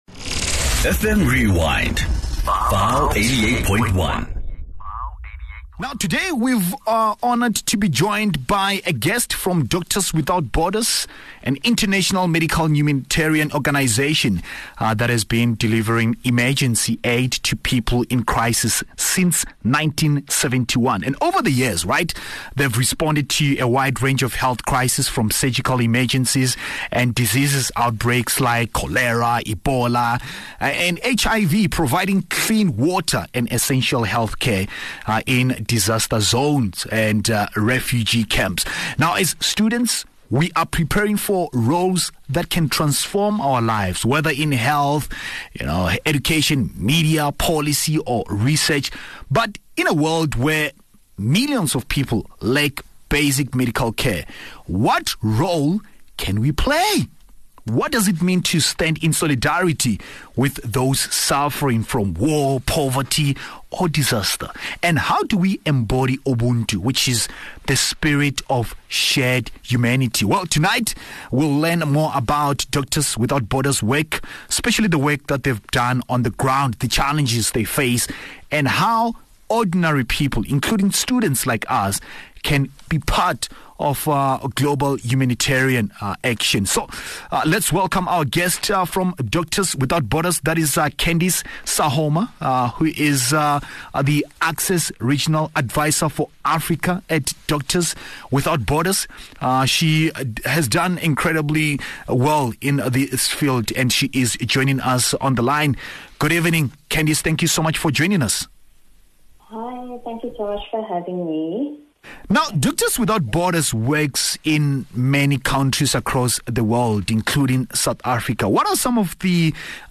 In conversation with